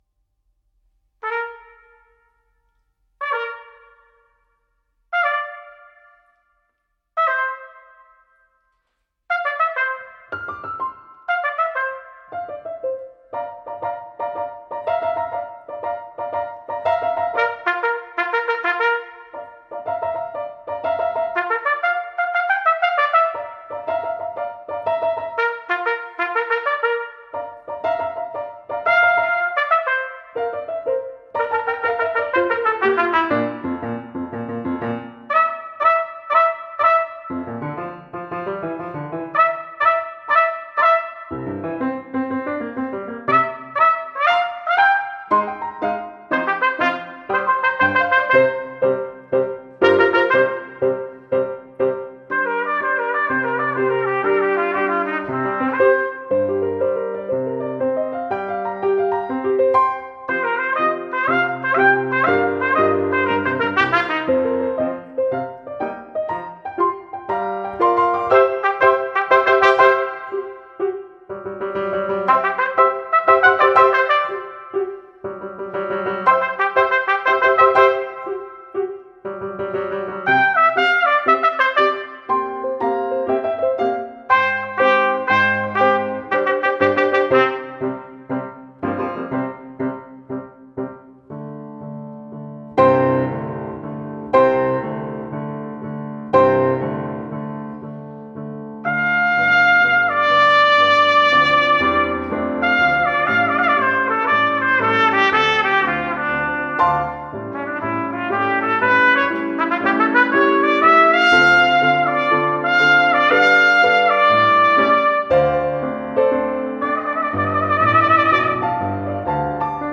for Trumpet and String Orchestra (2008)